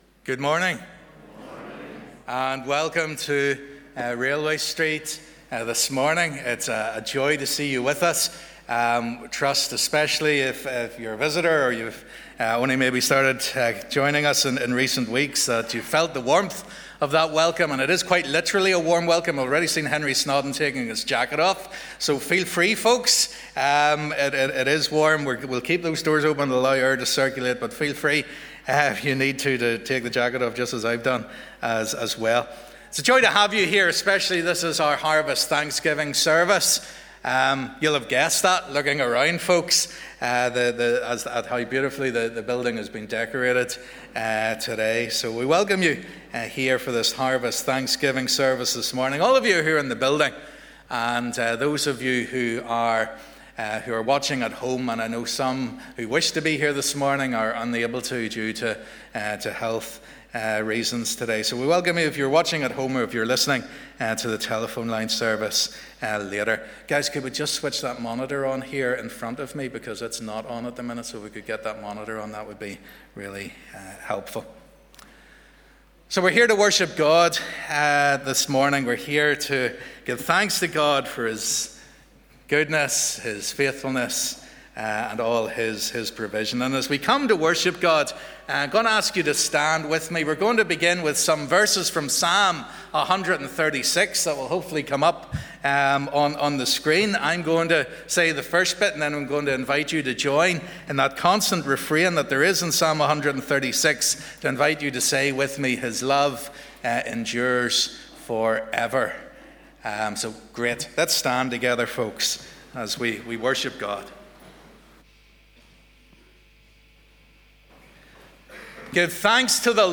'Harvest Thanksgiving Service 2023'